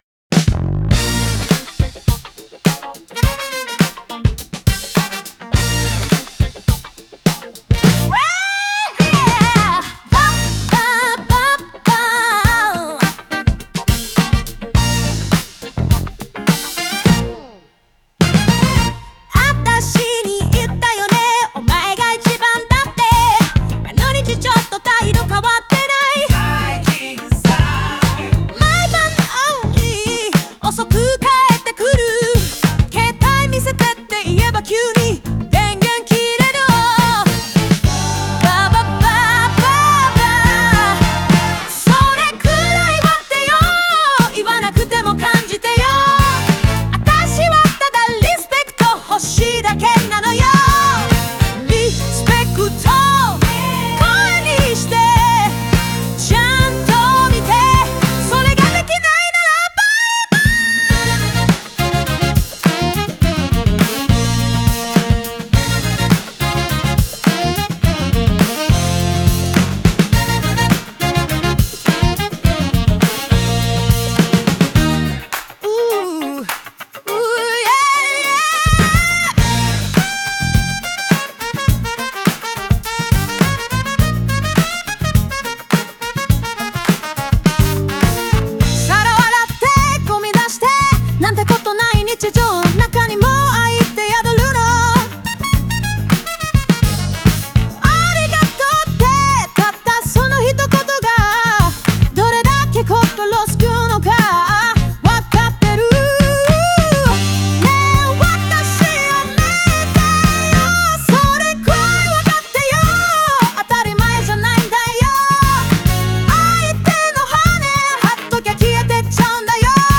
グルーヴ感あふれるホーンやコーラスとの掛け合いが感情を高め、最後までエネルギーが持続する構成も魅力です。